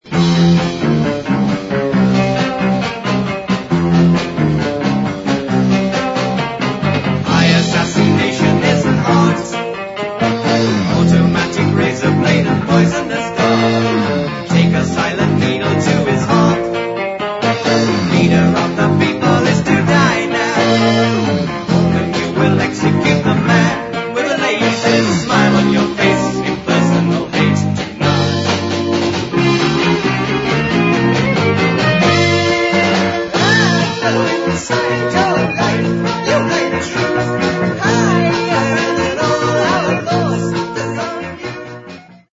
We taped the results as we went along.
Clips (mp3 medium quality stereo)